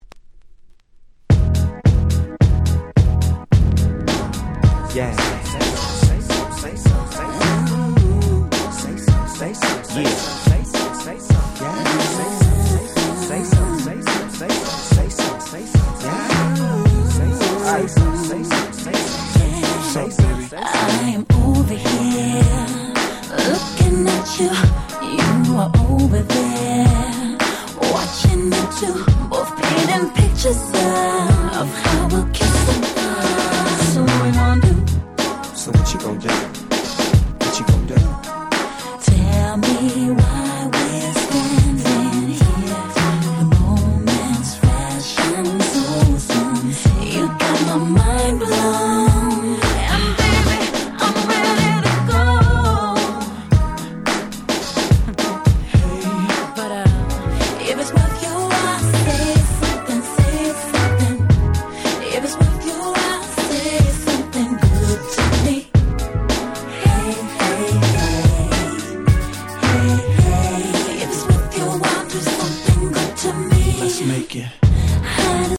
05' White Press Only R&B 12'' !!